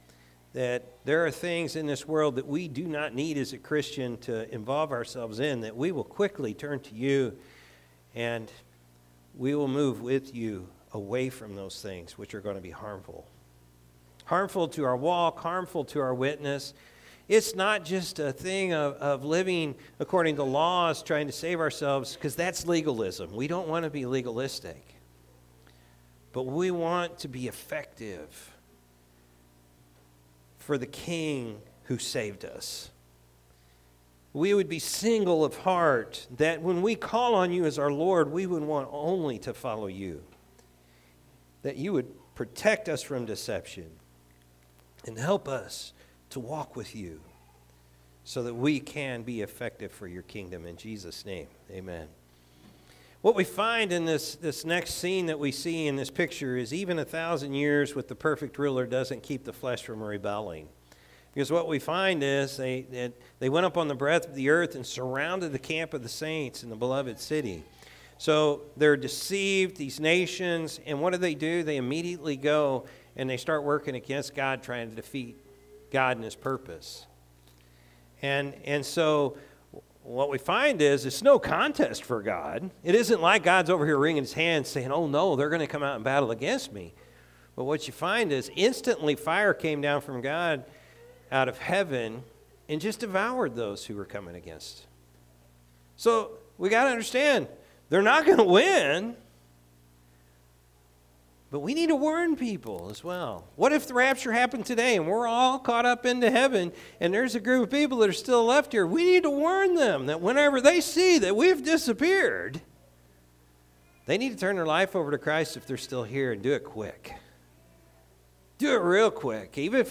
June-1-2025-Morning-Service.mp3